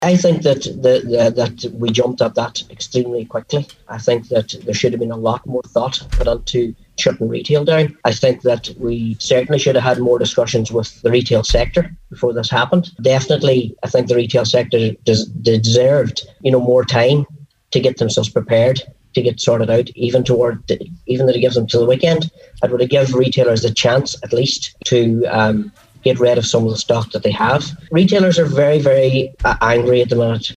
Speaking on today’s Nine Til Noon Show, Cllr Martin McDermott says while the Government have extremely difficult decisions to make, he feels this decision was rushed: